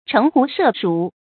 成語注音 ㄔㄥˊ ㄏㄨˊ ㄕㄜˋ ㄕㄨˇ
成語拼音 chéng hú shè shǔ
城狐社鼠發音